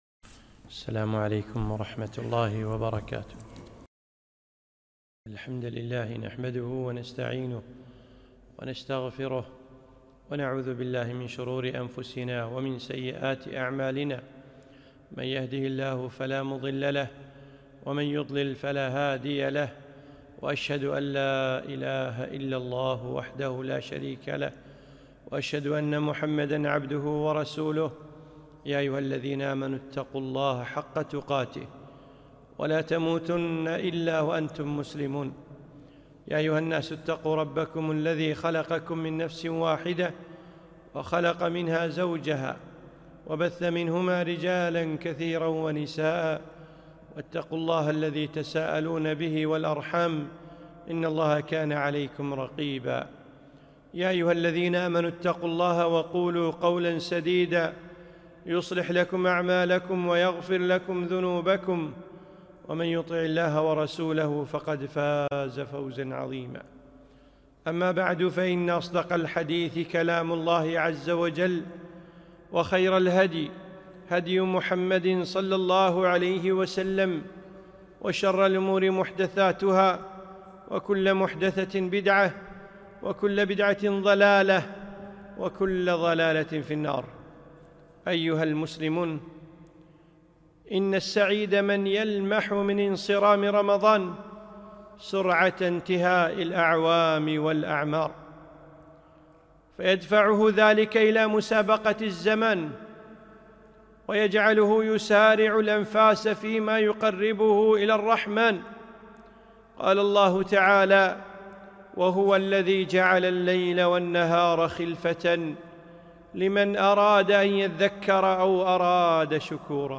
خطبة - وماذا بعد ؟